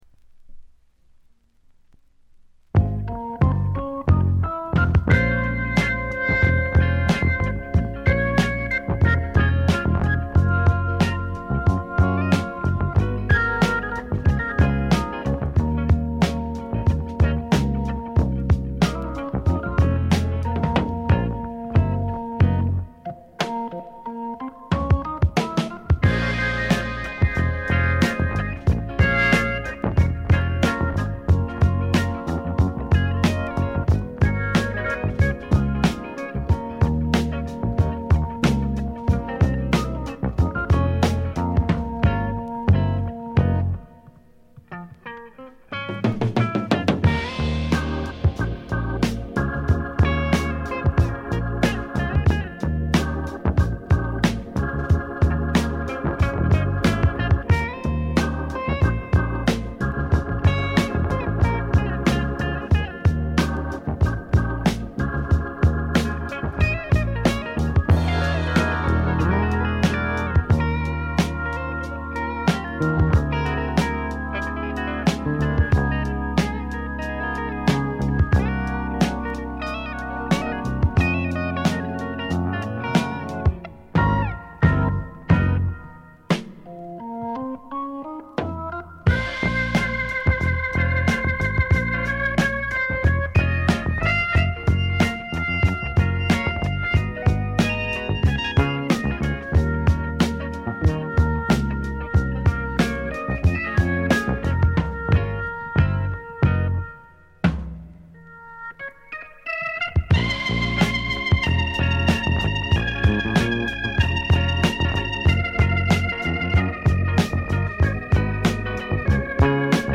これ以外はところどころで軽微なチリプチが少々。
フォーキーなアコースティック・グルーヴが胸を打つ名盤。
試聴曲は現品からの取り込み音源です。